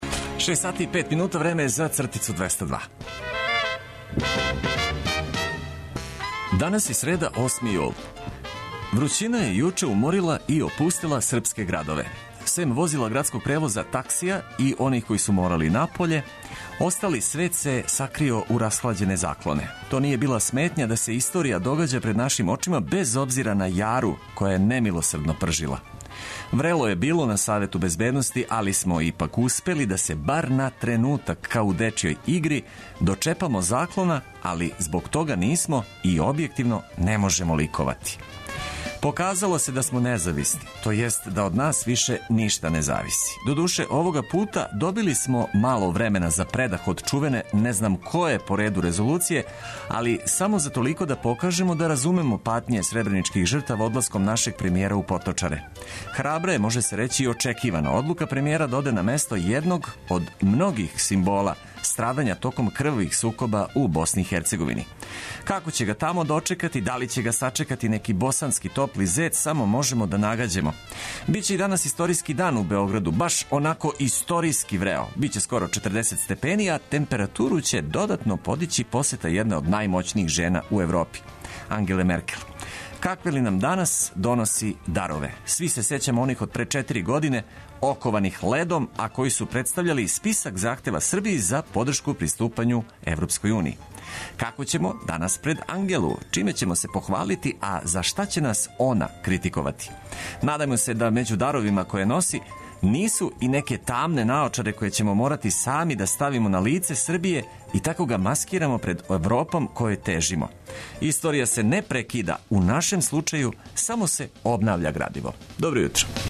Нека ова среда почне уз добру музику и информације од којих ћете имати користи.